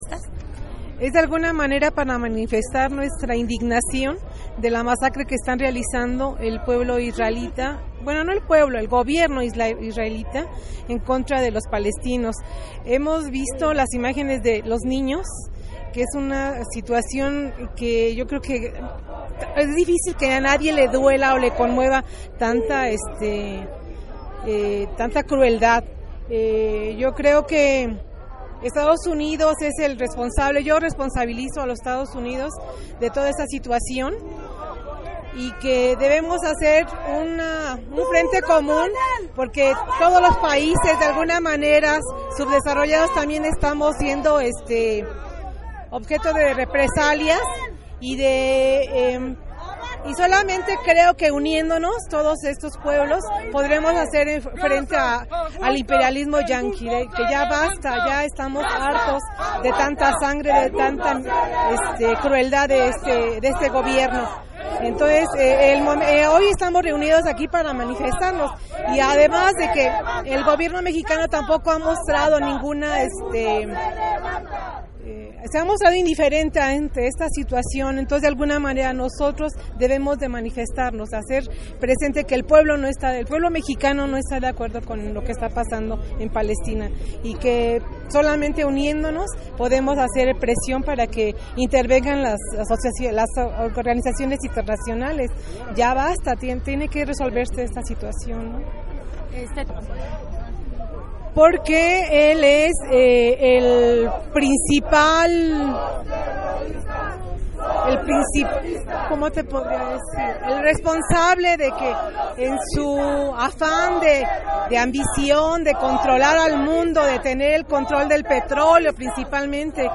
Entrevista compañera manifestante